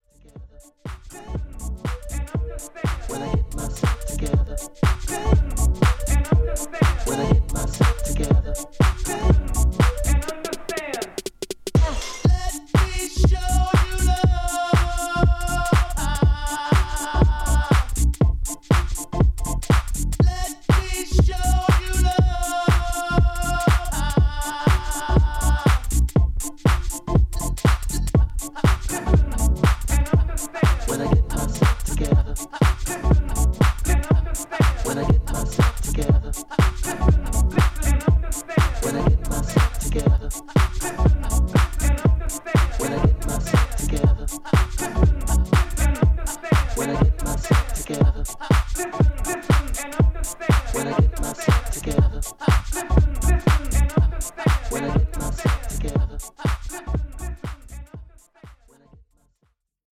ハウス